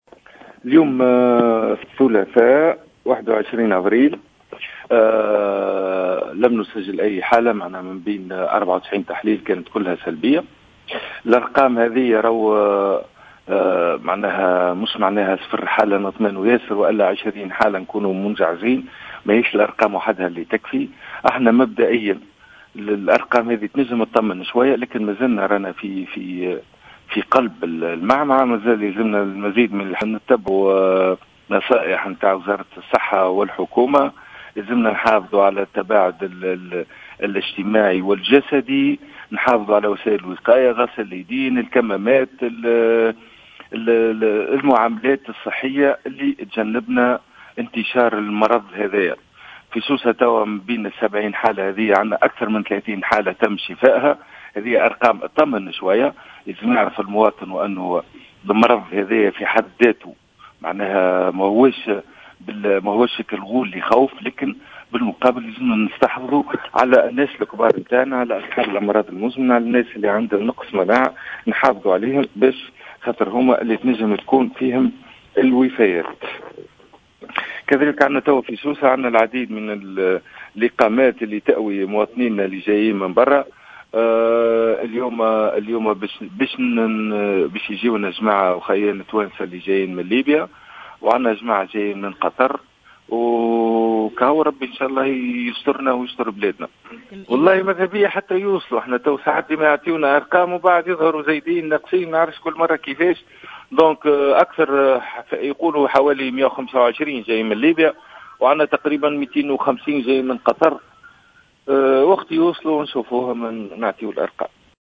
و قال الرقيق في تصريح للجوهرة أف أم، إنّ عدم تسجيل إصابات جديدة لا يعني تجاوز الخطر و نحن اليوم في قلب الأزمة، مشددا على ضرورة مواصلة احترام تدابير التوقي و تعليمات وزارة الصحة و الحكومة، و التباعد الاجتماعي، تجنبا لانتشار العدوى.